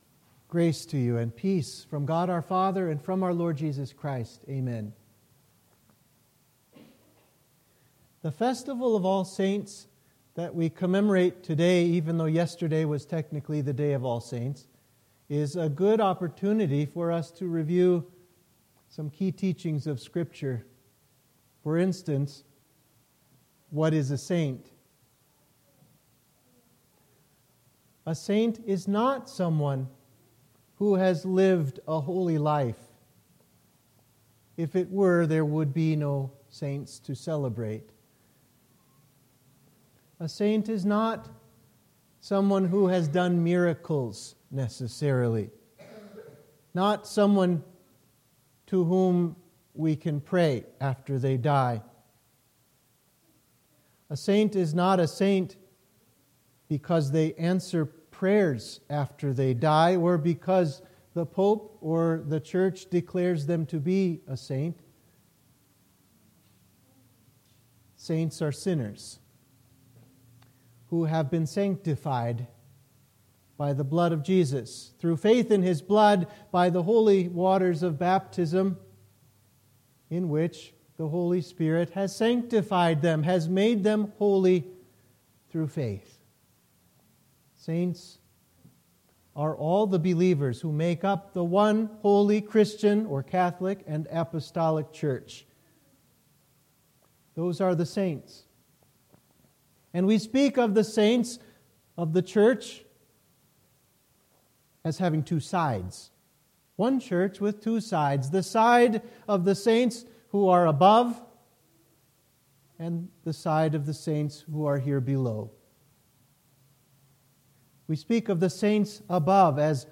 Sermon for the Festival of All Saints